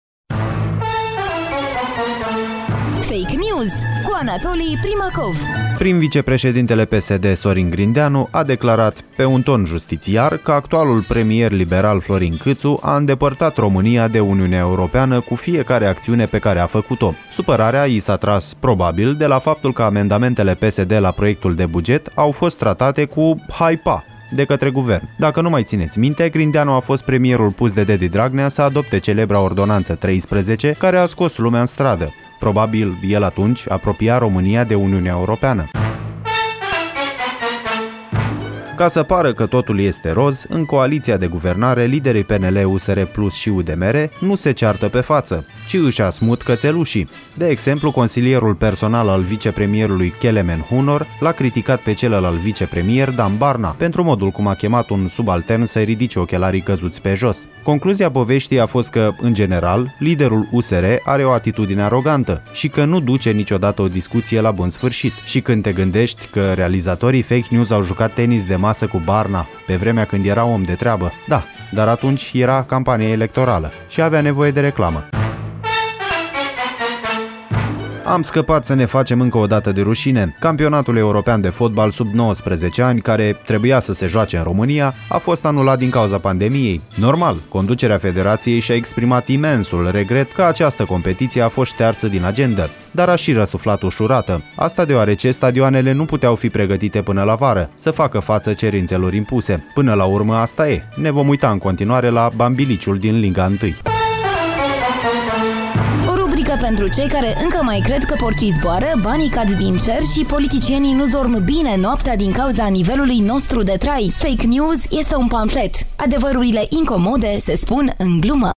Genul programului: pamflet.